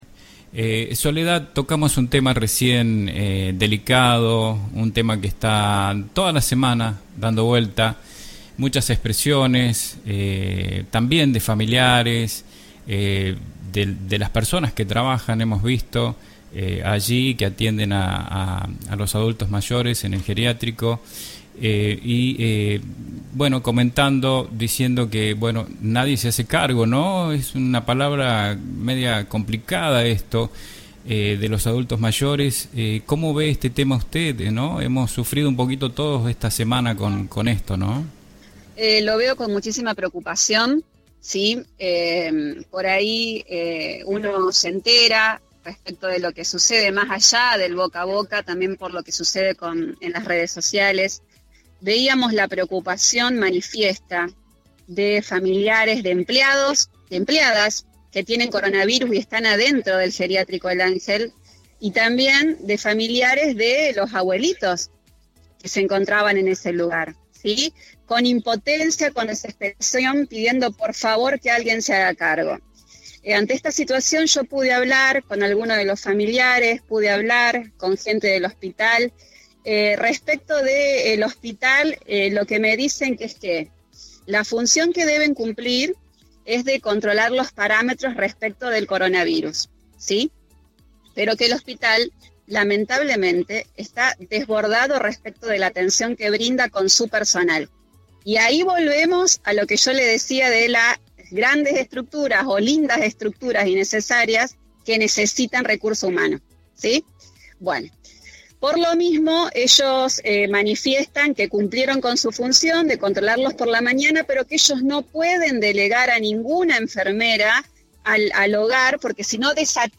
La Concejal Soledad Guirado del Bloque Vecinal Hablo en Todos Somos Noticia, Sobre el letargo del manejo y la postura del gobierno con respecto a la situación comprometida con contagios de los abuelos y las personas que lo cuidan a los mismos quienes también están contagiados de covid-19. en el hogar el Ángel.